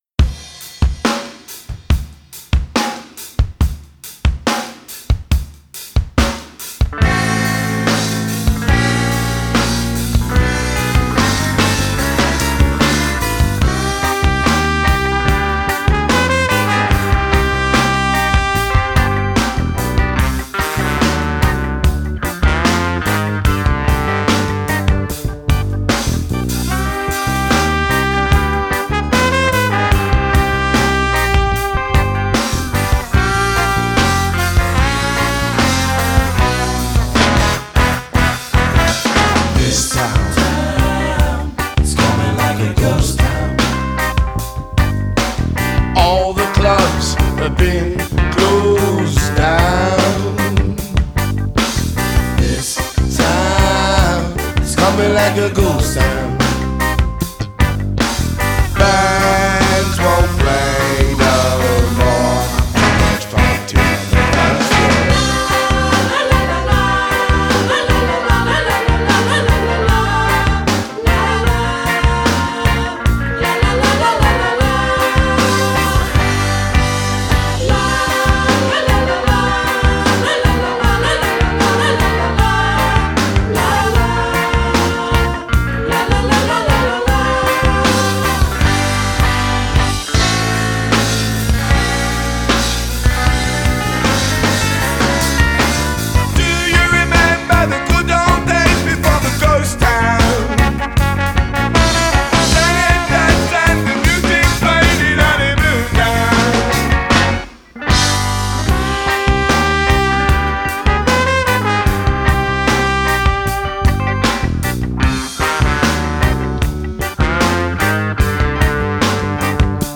Genre: Ska, Two-Tone, New Wave, Reggae